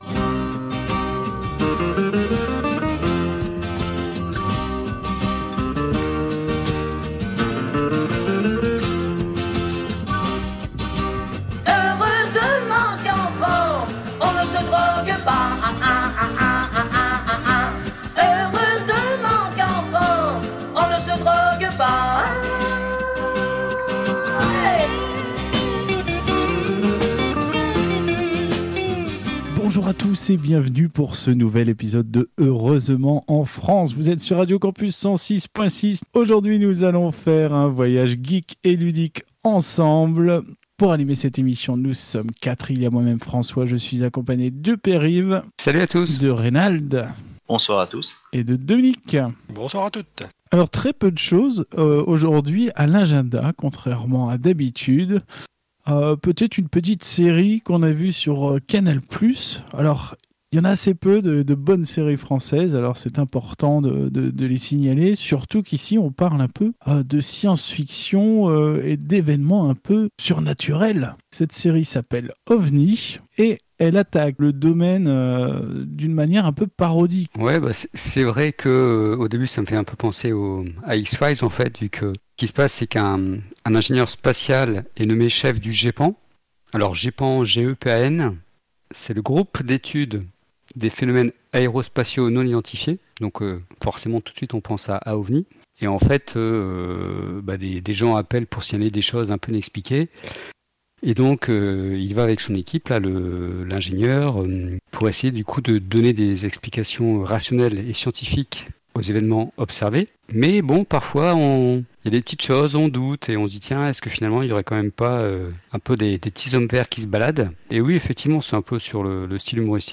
Au sommaire de cet épisode diffusé le 31 janvier 2021 sur Radio Campus 106.6 :